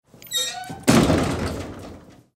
Chirrido de una puerta de bar al cerrarse